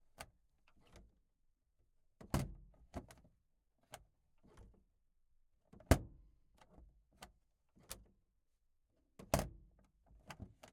Volkswagen_Van_t12_Var_SFX_Glove_Compartment_XY_RSM191.ogg